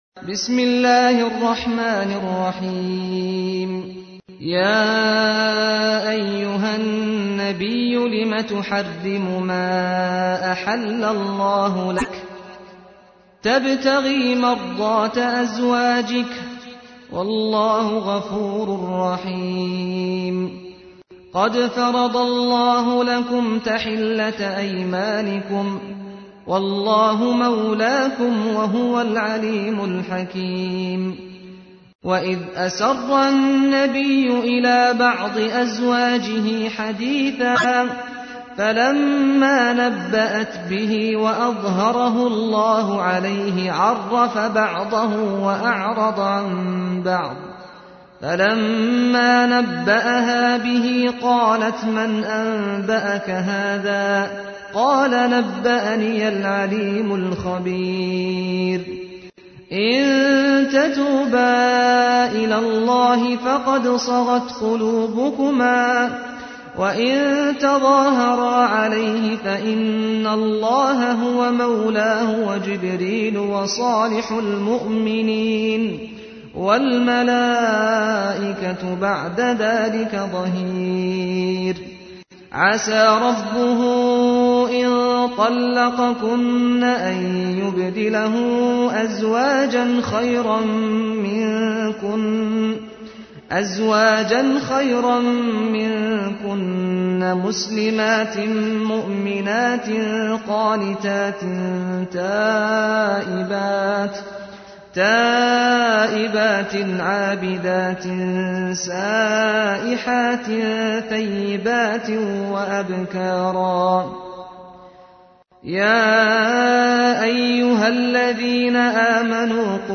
تحميل : 66. سورة التحريم / القارئ سعد الغامدي / القرآن الكريم / موقع يا حسين